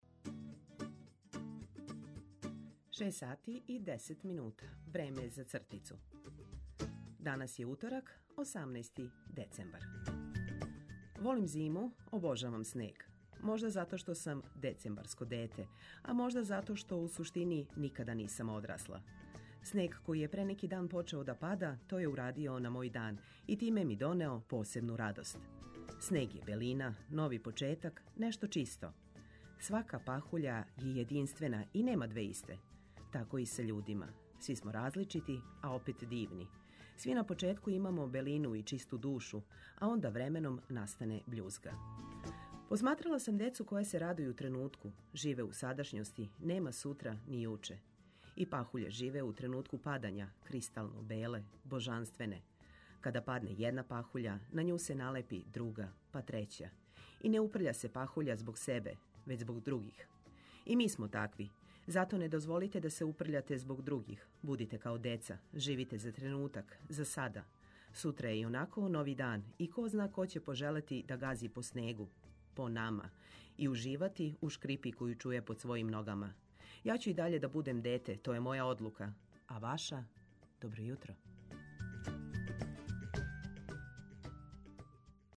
Будући да је дан пред Светог Николу, наш репортер истражује каква је понуда рибе на пијацама. Добра музика, расположена екипа и Ви, добитна комбинација за успешан почетак дана.